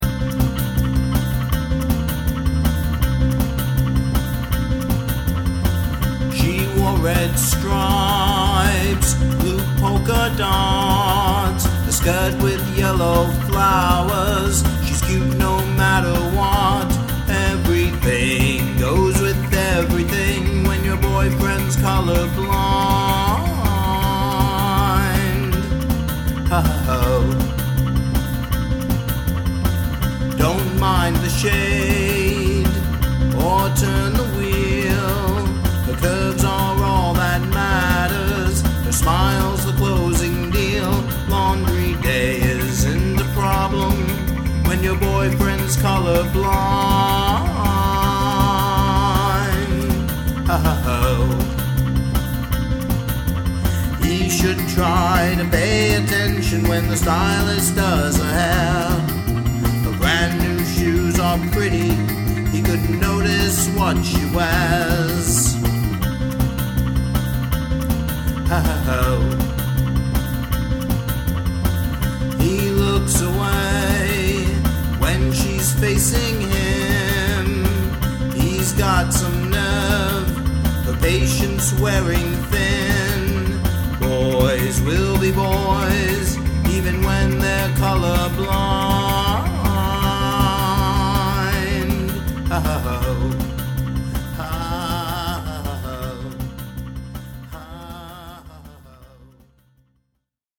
Song cannot have a chorus.